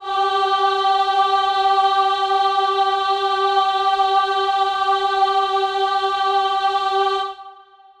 Choir Piano
G4.wav